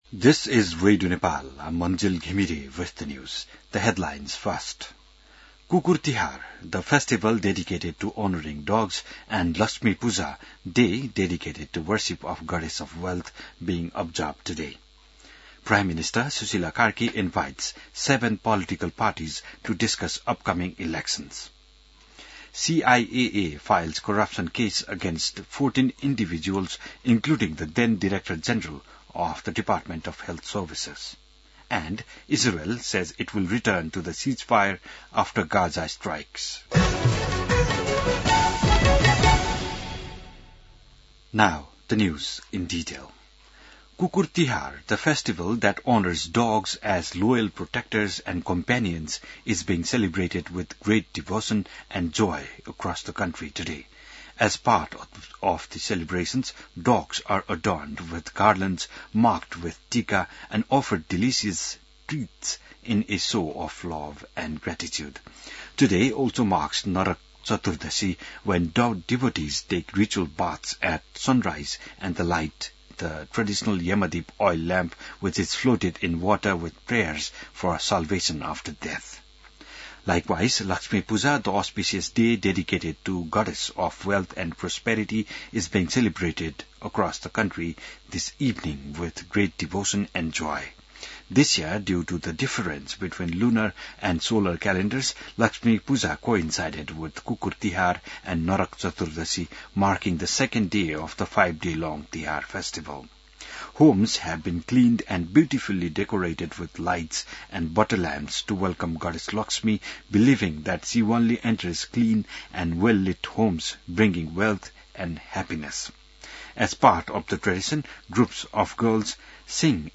बिहान ८ बजेको अङ्ग्रेजी समाचार : ३ कार्तिक , २०८२